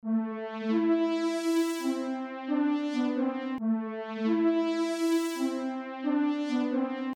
标签： 135 bpm Breakbeat Loops Drum Loops 1.20 MB wav Key : Unknown
声道立体声